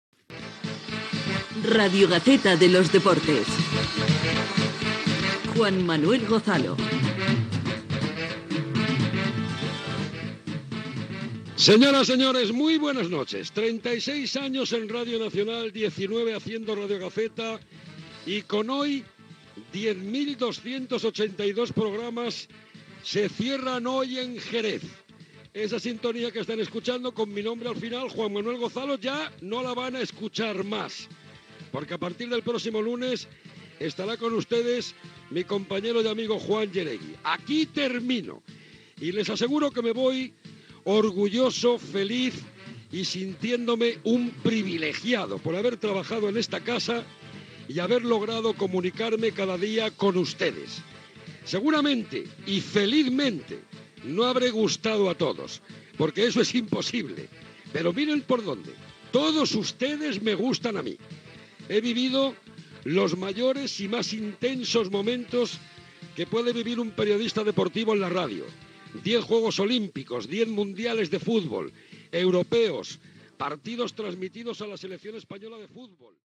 Indicatiu del programa, presentació de l'última edició del programa que presenta Juan Manuel Gozalo
Esportiu